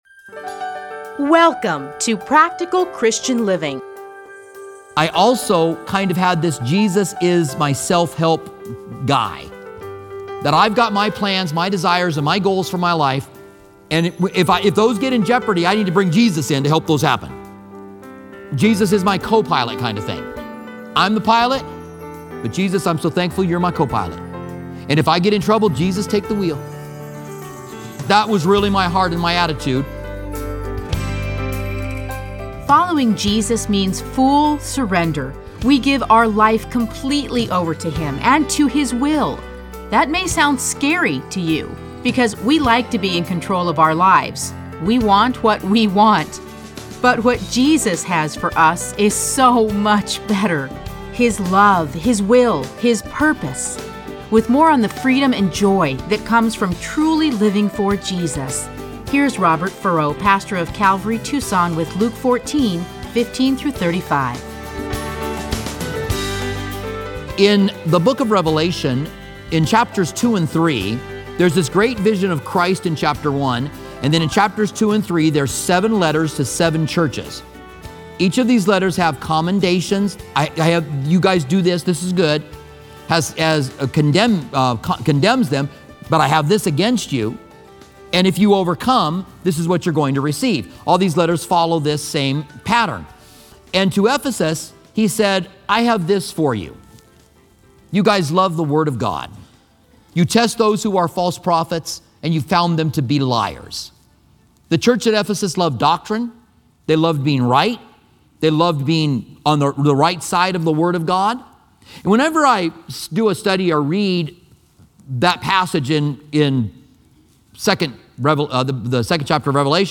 Listen to a teaching from Luke 14:15-35.